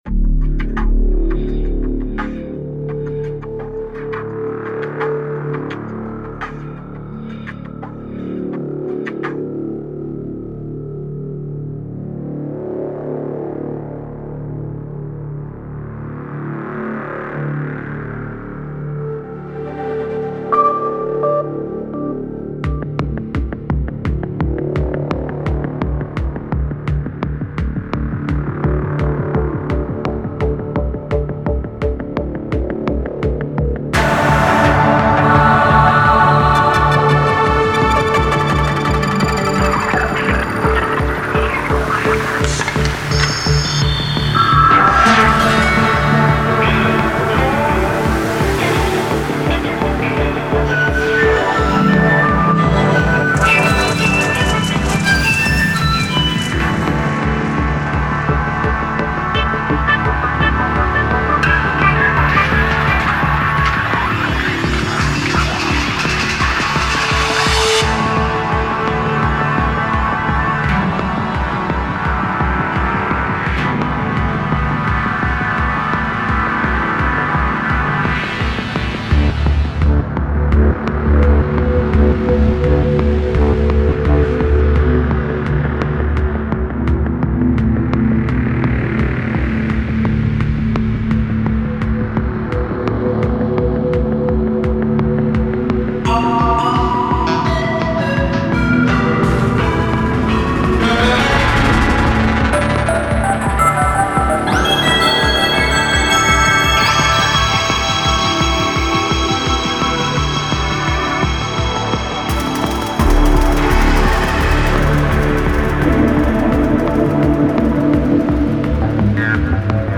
Genre: Psybient.